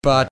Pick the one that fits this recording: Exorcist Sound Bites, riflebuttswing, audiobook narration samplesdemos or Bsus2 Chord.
riflebuttswing